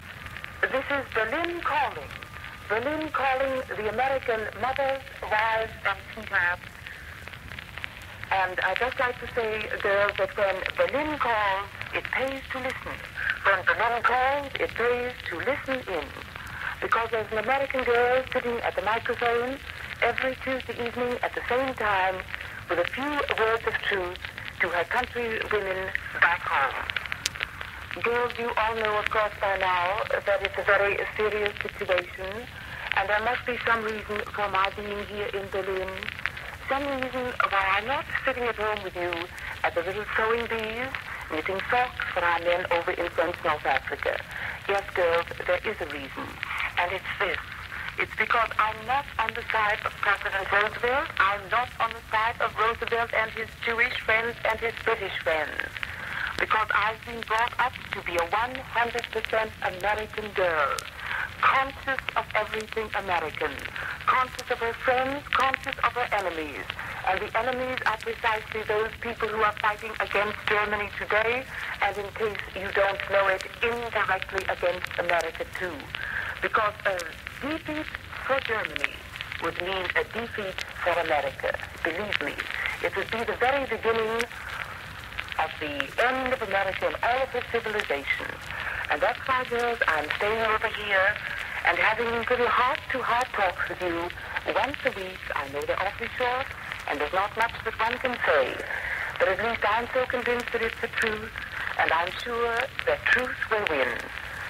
Once America entered the war, the Nazis began broadcasting defeatist propaganda using an American living in Berlin. Here is Mildred Gillars, often referred to as “Axis Sally,” explaining why she’s in Berlin instead of back home at “the little sewing bees” with the girls. Note that, although she was presenting herself as “100 percent American,” she spoke with a strong British accent. She also refers to soldiers in South Africa, when she means American soldiers in the north Africa campaign.